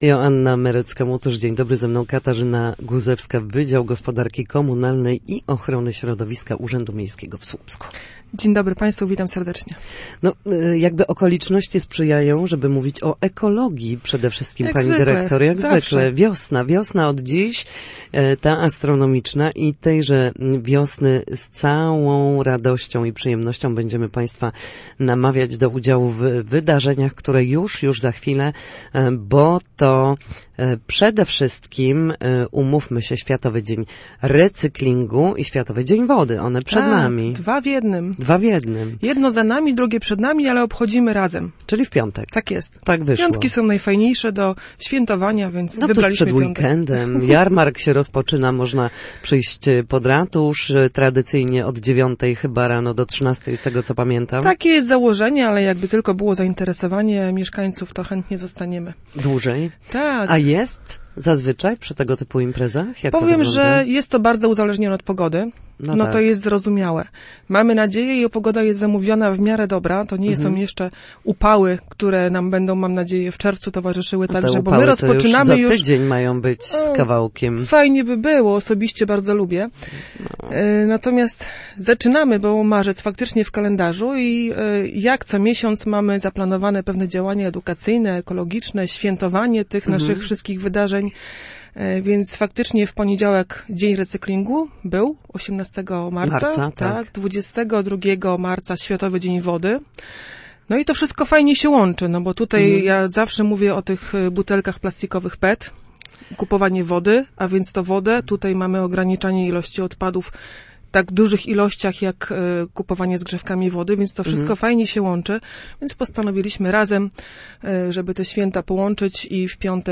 Na naszej antenie mówiła między innymi o zbliżającej się imprezie z okazji obchodów Dnia Recyklingu oraz Światowego Dnia Wody, o wymianie kopciuchów, konkursach ekologicznych i korzyściach, jakie płyną z edukacji.